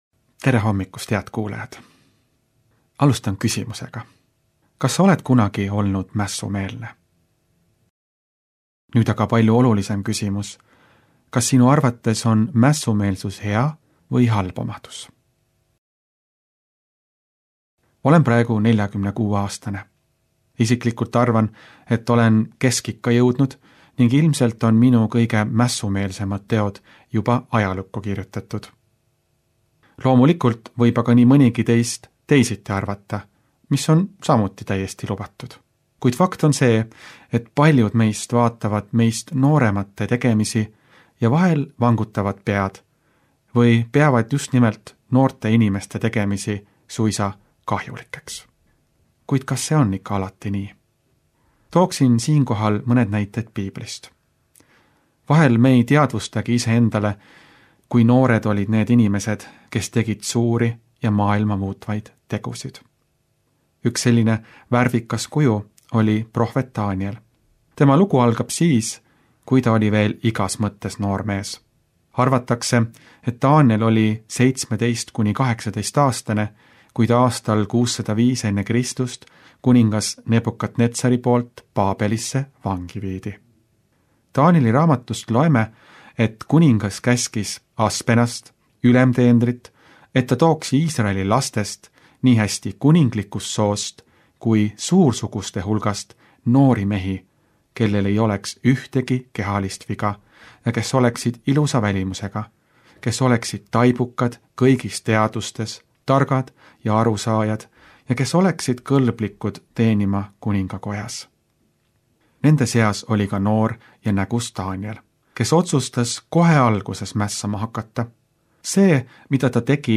hommikupalvus Pereraadios 08.11.2025
Hommikupalvused